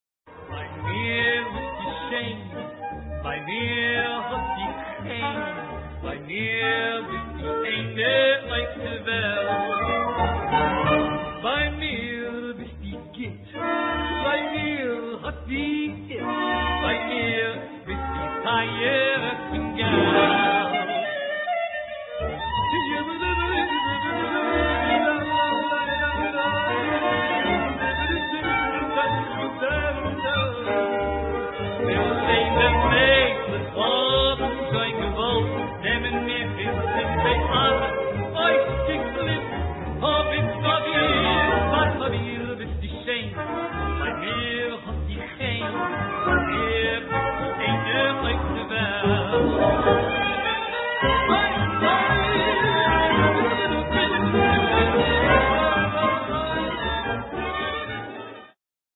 эмоциональное исполнение